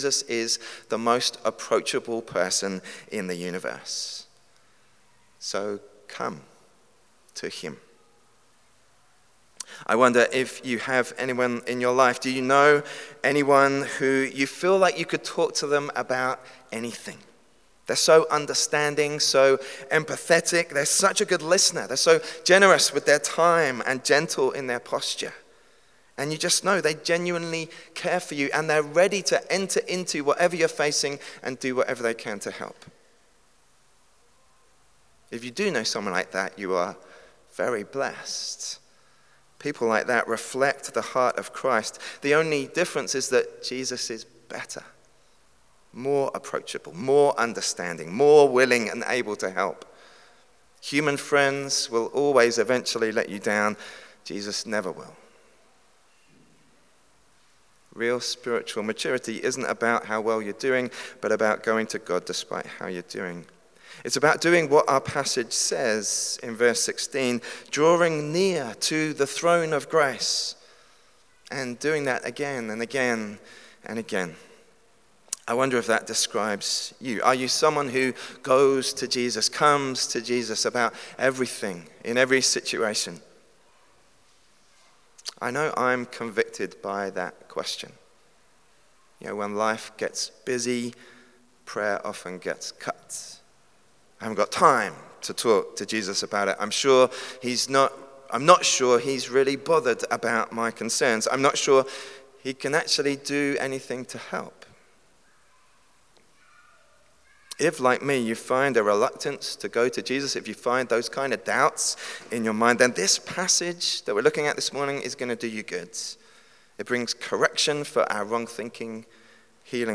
sermon-15.3.26.mp3